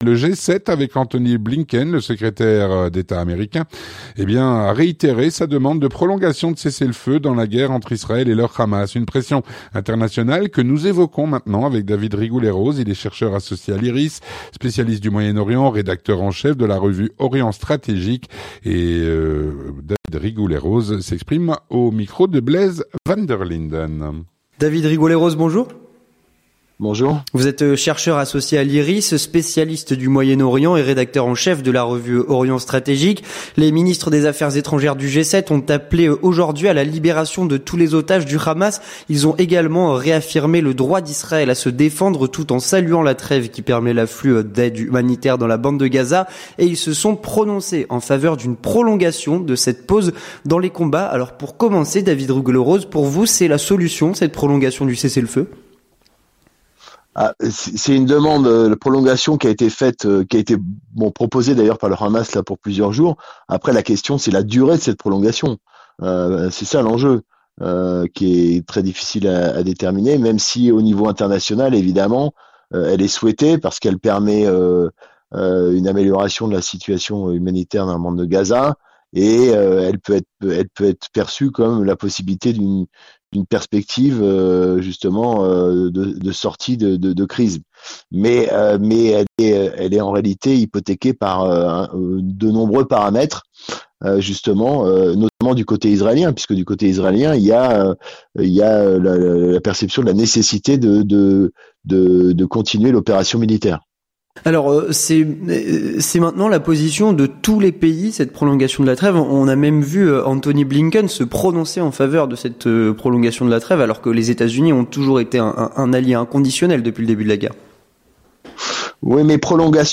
L'entretien du 18H - Guerre Israël/Hamas : Le G7 a réitéré sa demande de prolongation de cessez-le-feu.